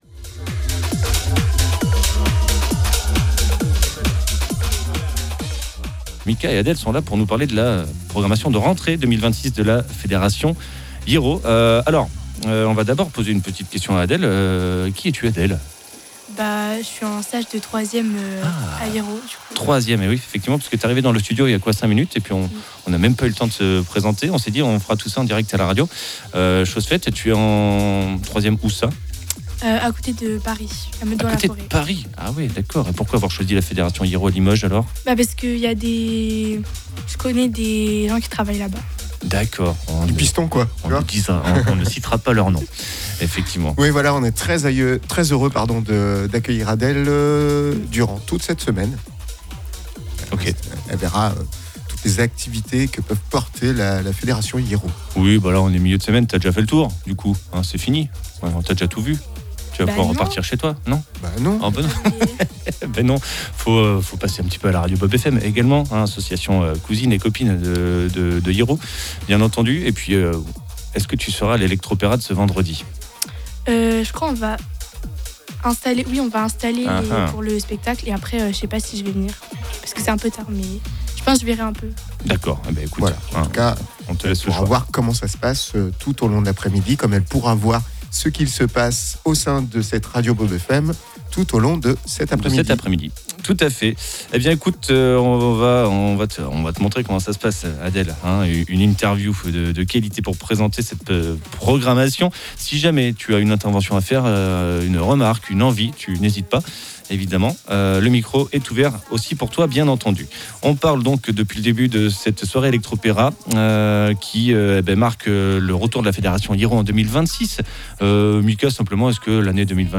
ITW-HIERO-1ER-TRIMESTRE-2026.mp3